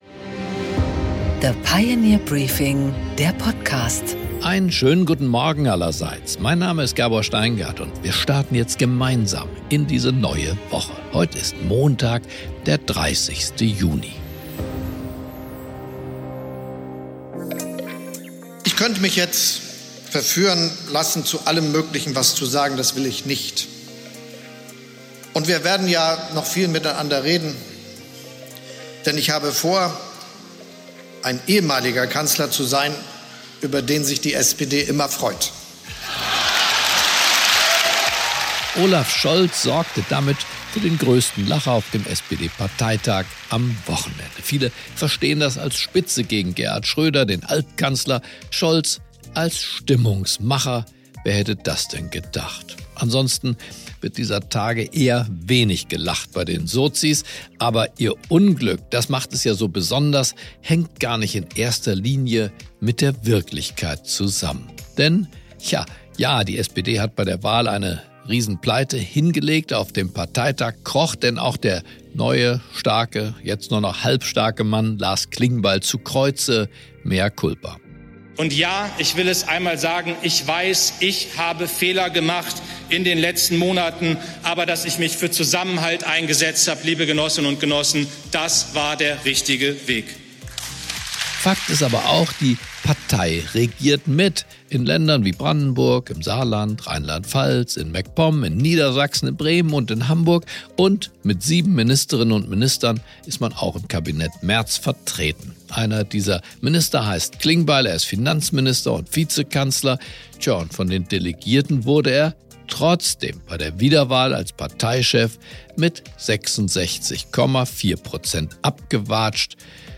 Gabor Steingart präsentiert das Pioneer Briefing
Im Interview: Ralf Stegner, MdB (SPD), spricht mit Gabor Steingart über den SPD-Parteitag, das schlechte Wahlergebnis für Lars Klingbeil als Parteichef und das Thema Friedenspolitik.